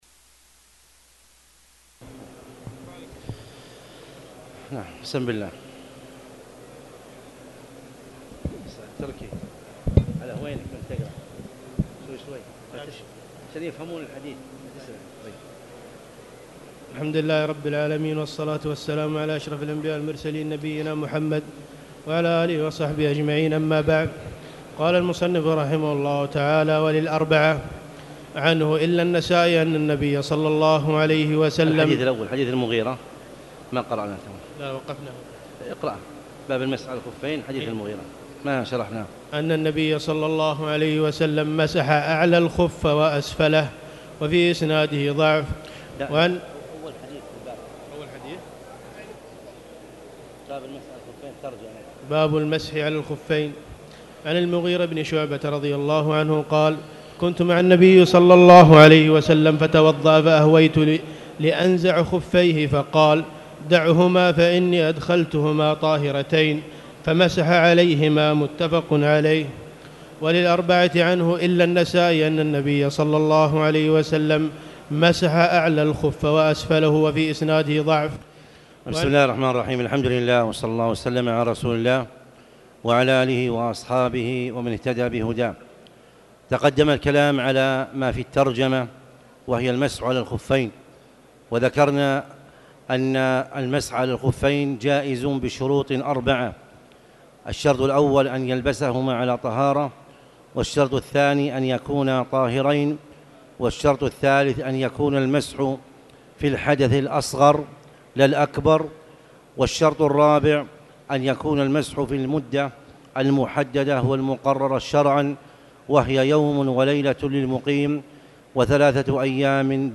تاريخ النشر ٢٤ ربيع الأول ١٤٣٨ هـ المكان: المسجد الحرام الشيخ